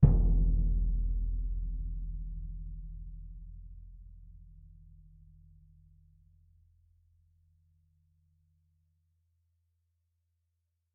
bassdrum_hit_f.mp3